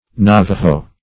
Navajoes \Na"va*joes\, n. pl.; sing. Navajo. (Ethnol.)